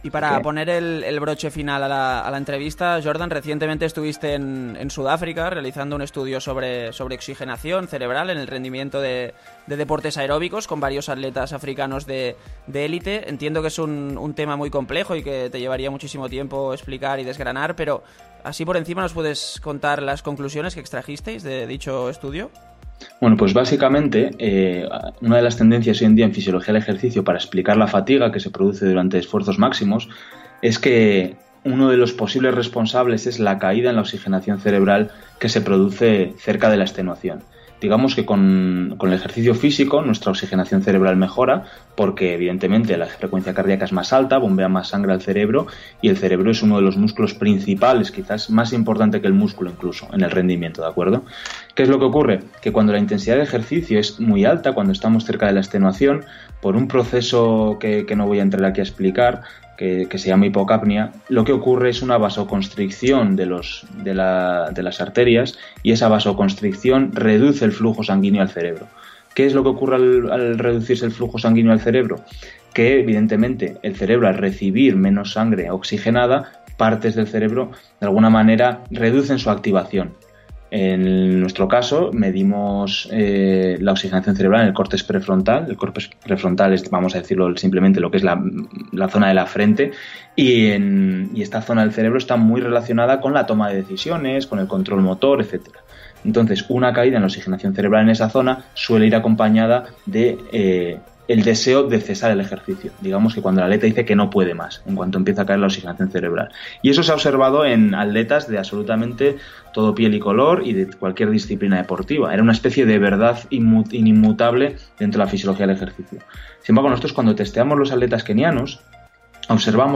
En la Radio del Corredor le preguntamos por ello.